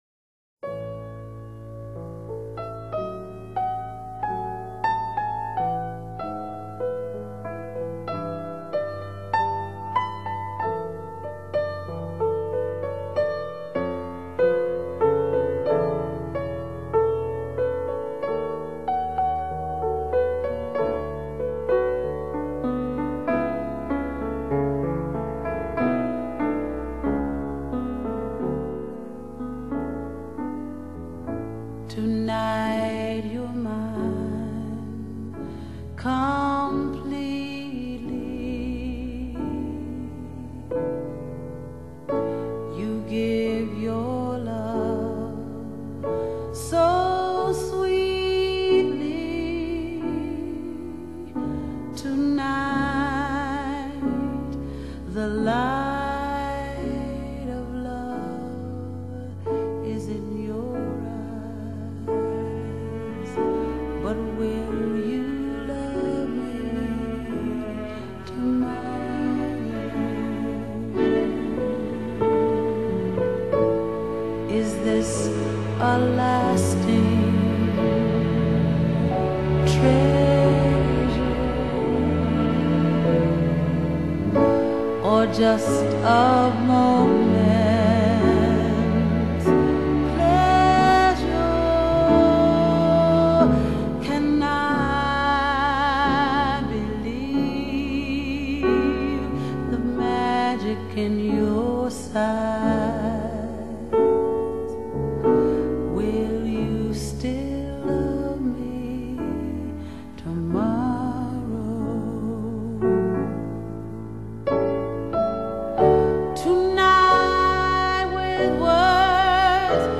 a soft rendition
jazz and gospel vocals
measured intensity and sighing, elongated phrases